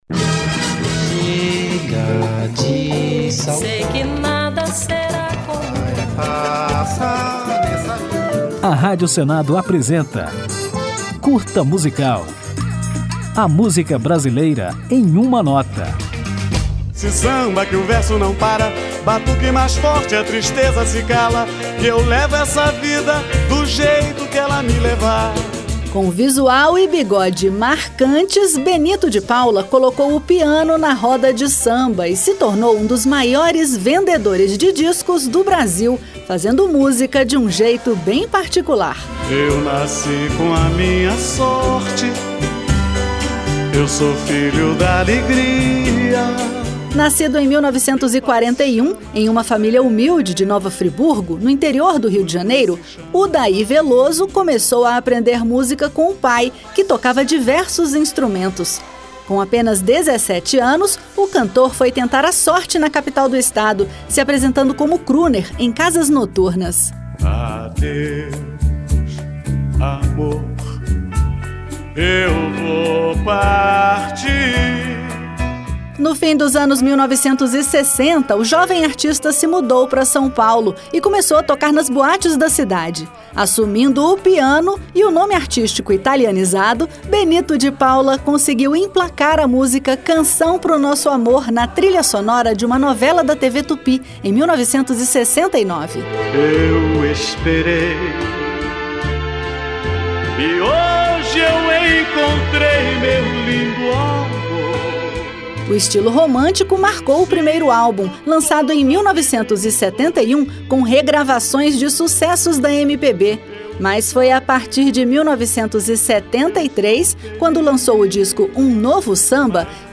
Com um estilo particular de fazer samba, marcado pelo piano na linha de frente, Benito di Paula fez um enorme sucesso nas décadas de 1970 e 1980, vendendo dezenas de milhões de discos. Nesse Curta Musical você vai conhecer um pouco da história do compositor e ainda vai ouvir um dos seus maiores sucessos, a música Retalhos de Cetim, destaque do álbum Um Novo Samba, de 1973.